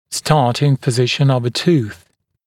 [‘stɑːtɪŋ pə’zɪʃn əv ə tuːθ][‘ста:тин пэ’зишн ов э ту:с]положение зуба в начале движения